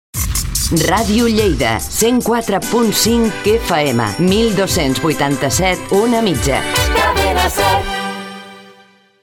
Indicatiu de l'emissora i freqüències d'OM i FM